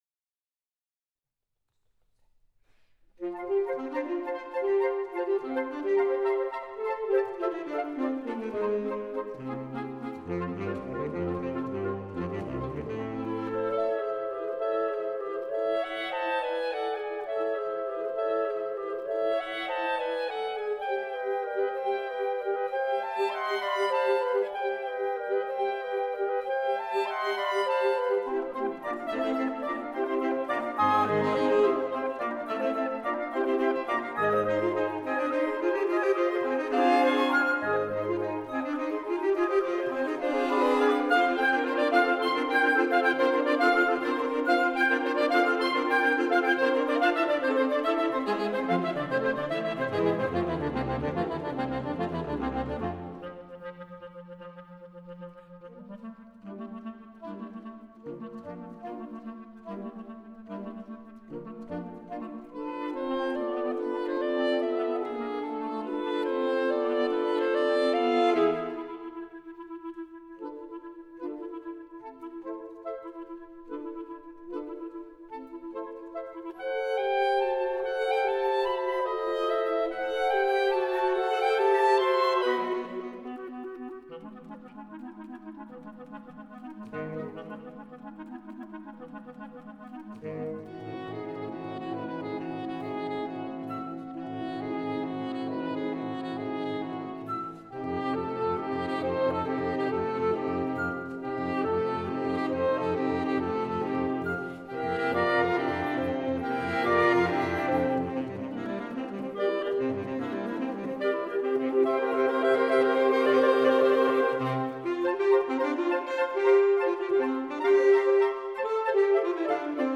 Partitions pour ensemble flexible, 7-8-voix.